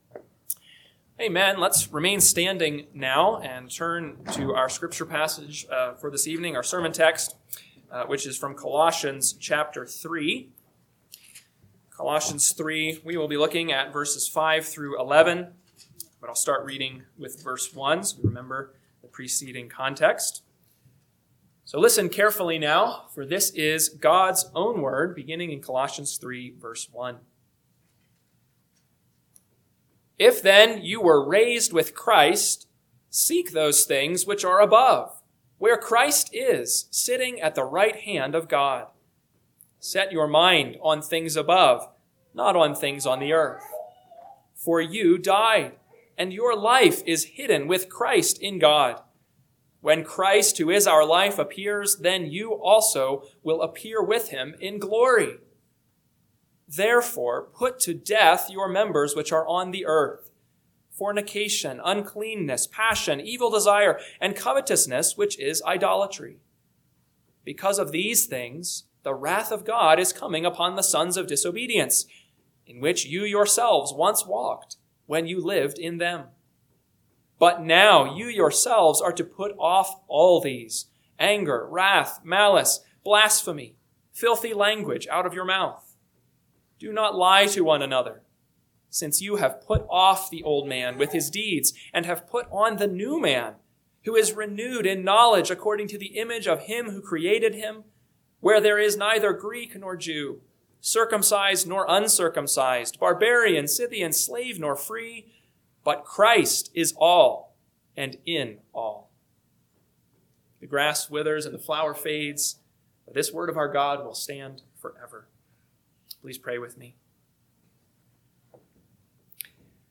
PM Sermon – 3/29/2026 – Colossians 3:5-11 – Northwoods Sermons